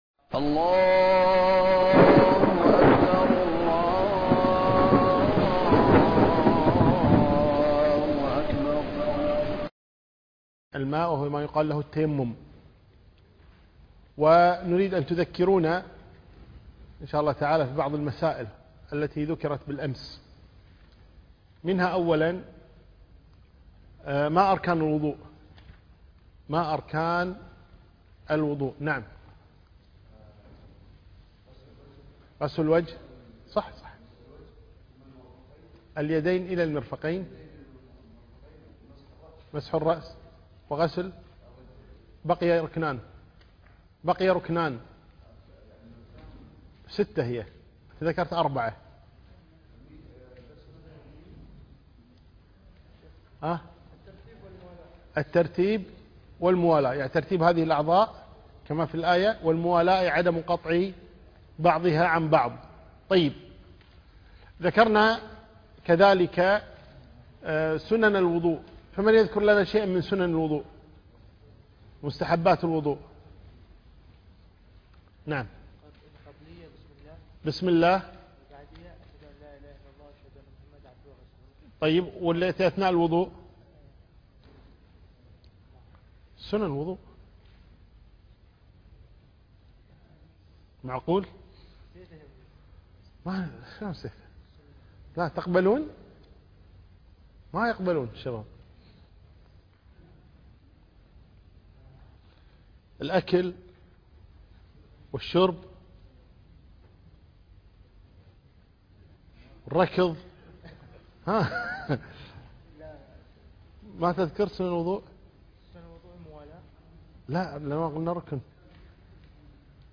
الدرس الثاني - فقه الصلاة - الشيخ عثمان الخميس